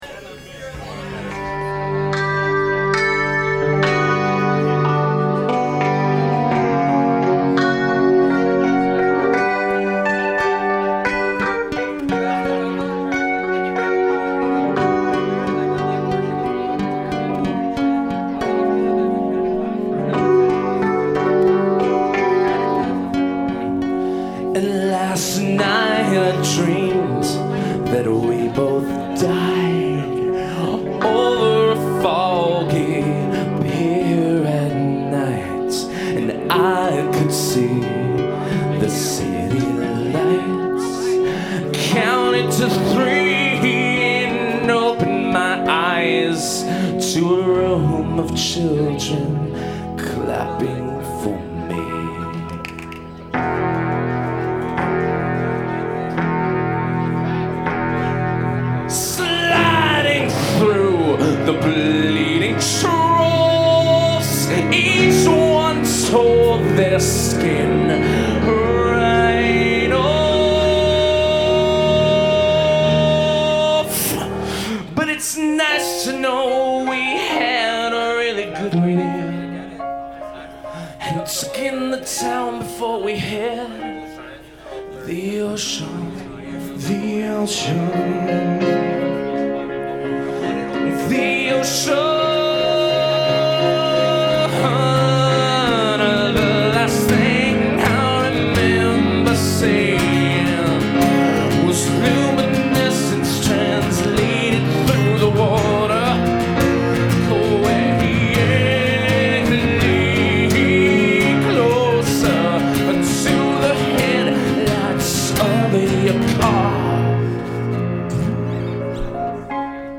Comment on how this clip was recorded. (live) soundboard recording